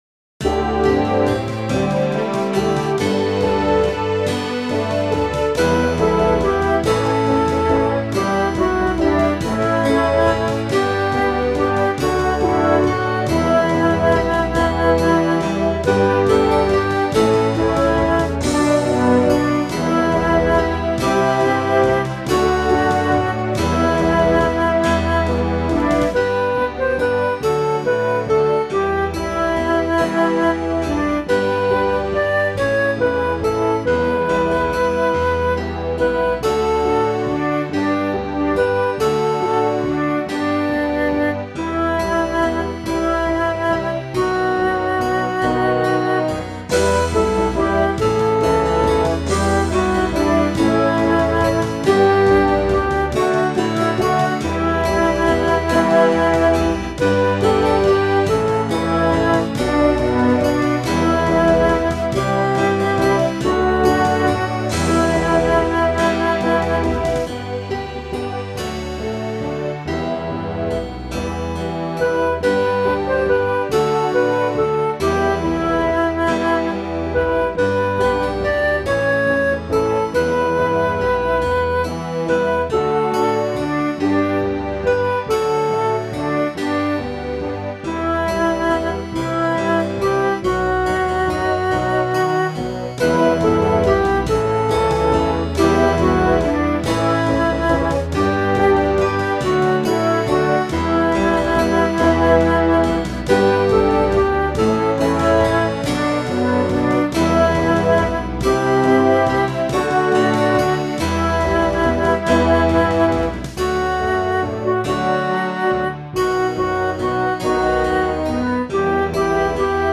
It is meant to be “yearning and insistent” and the the slow drawn out “bring us back” in the refrain is certainly emphatic.